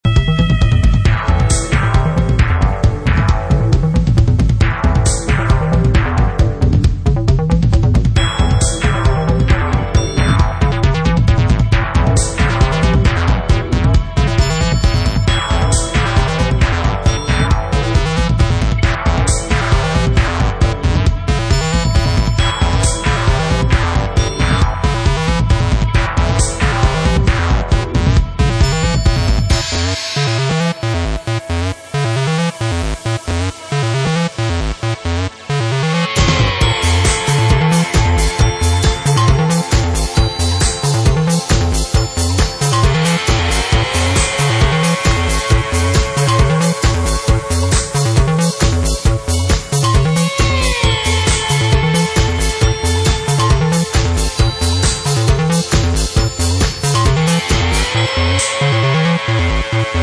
They play DISCO.